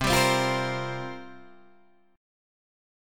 C7sus4 chord